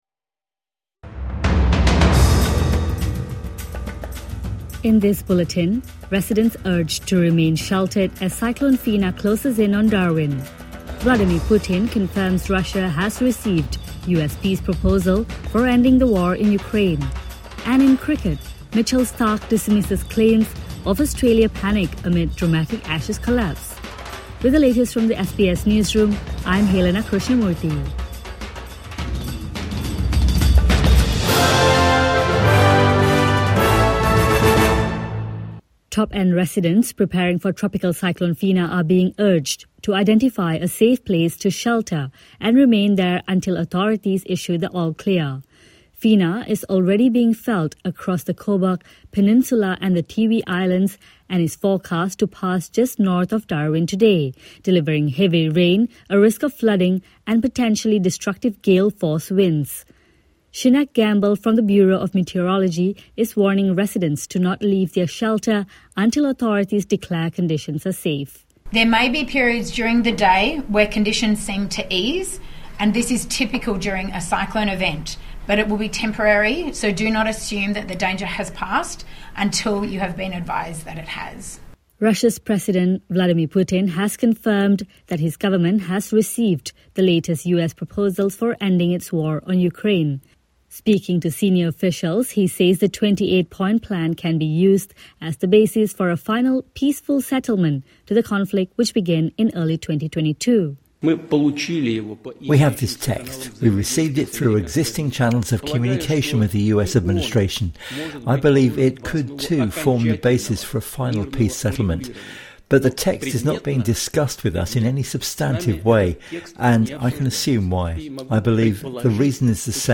Residents urged to remain sheltered as Cyclone Fina closes in on Darwin | Morning Bulletin 22 November 2025